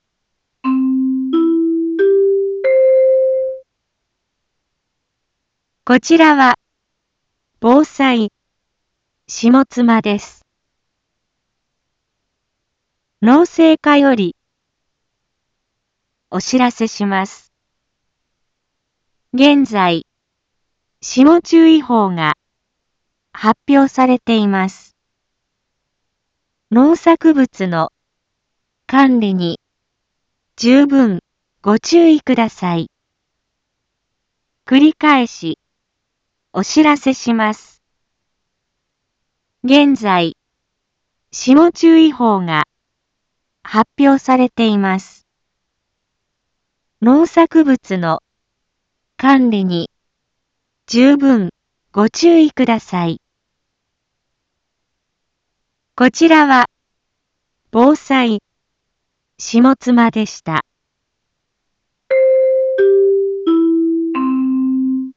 Back Home 一般放送情報 音声放送 再生 一般放送情報 登録日時：2021-04-23 18:01:08 タイトル：霜注意報 インフォメーション：こちらは防災下妻です。